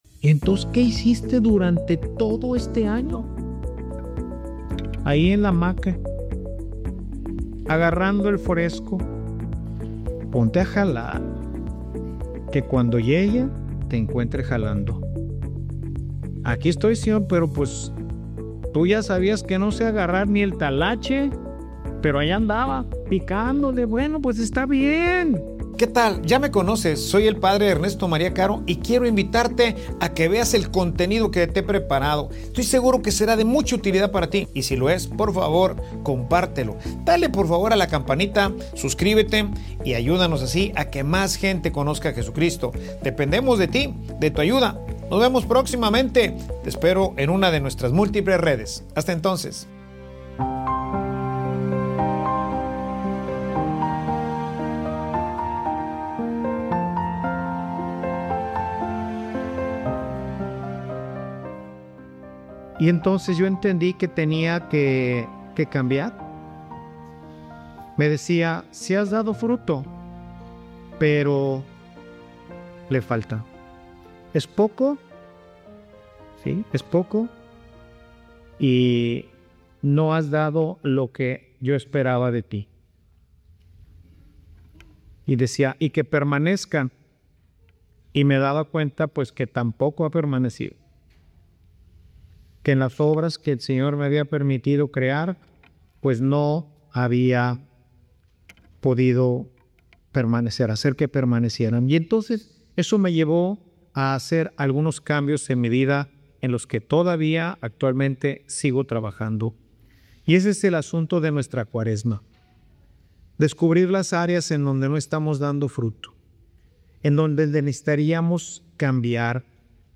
Homilia_El_costo_de_compararse.mp3